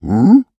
Minecraft Version Minecraft Version snapshot Latest Release | Latest Snapshot snapshot / assets / minecraft / sounds / mob / piglin / jealous5.ogg Compare With Compare With Latest Release | Latest Snapshot
jealous5.ogg